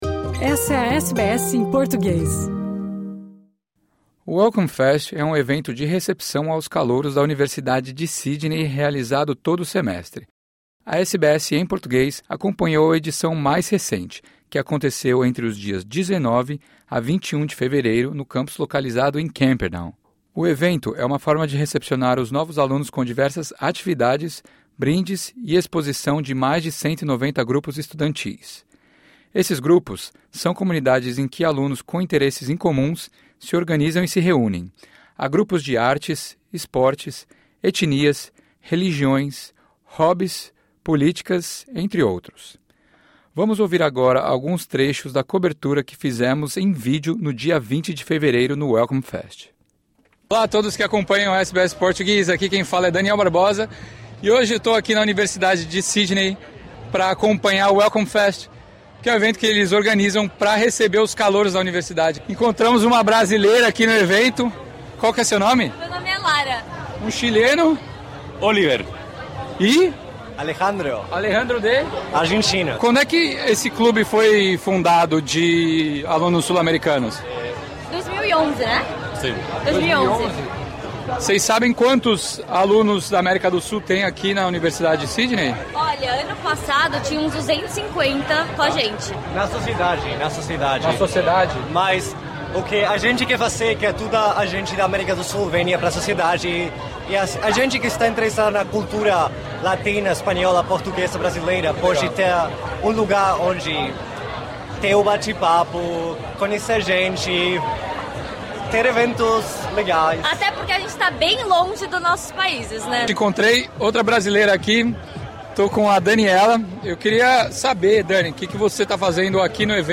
A SBS em Português esteve no campus da Universidade de Sydney para acompanhar o Welcome Fest. O evento de boas-vindas é realizado a cada semestre e tem como objetivo recepcionar e acolher os alunos com diversas atividades.